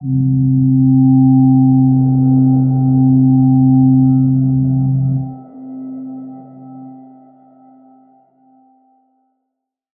G_Crystal-C4-pp.wav